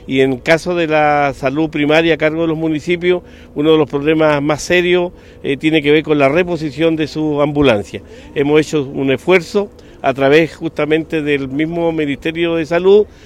Con la típica postal de la costanera de Valdivia de fondo y con los vehículos de emergencia formados en el patio del Gobierno Regional, se realizó la ceremonia de entrega de los móviles destinados a la salud primaria de Lago Ranco, Máfil, La Unión, Panguipulli, Lanco y Futrono.
El gobernador de Los Ríos, Luis Cuvertino, indicó que en la atención primaria, una de las principales falencias se relaciona con la disposición de vehículos de emergencia.